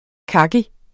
Udtale [ ˈkɑgi ]